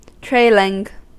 Ääntäminen
Ääntäminen US : IPA : [tɹeɪl.ɪŋ] Haettu sana löytyi näillä lähdekielillä: englanti Käännöksiä ei löytynyt valitulle kohdekielelle.